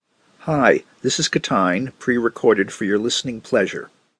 nice announcer voice.
Man I was so close.